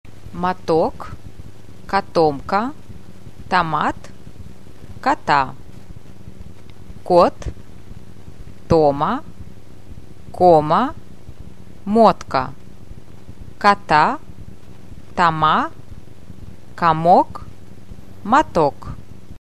Hören Sie zu und sprechen Sie nach.
Seite 17Lektion 2Übung 1Unbetontes O